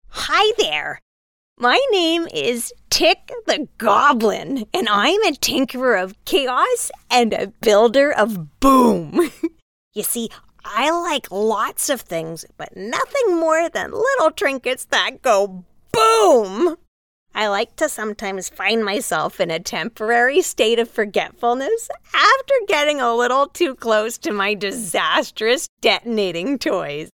Character Sample (EN)
Clients describe her sound as engaging, distinct, and easy on the ears - like a trusted guide who knows how to have fun.
Broadcast-quality home studio | Fast, reliable turnaround | Friendly, professional, and directable